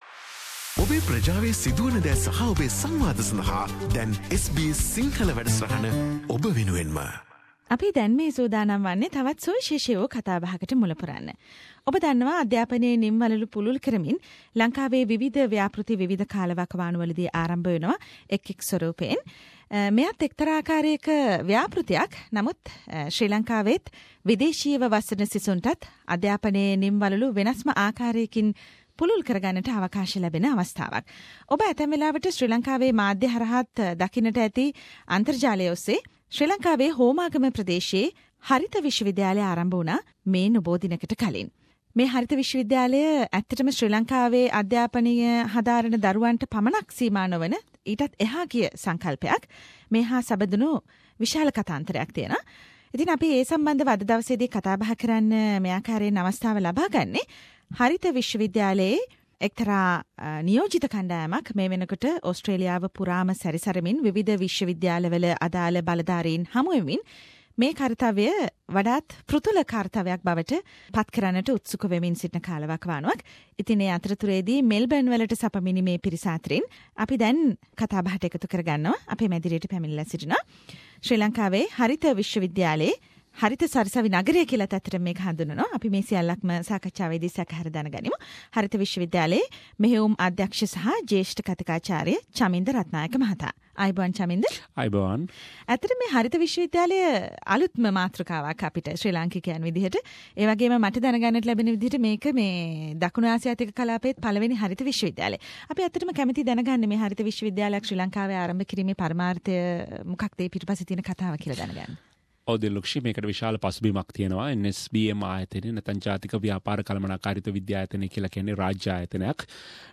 visited SBS studios